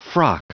Prononciation du mot frock en anglais (fichier audio)
Prononciation du mot : frock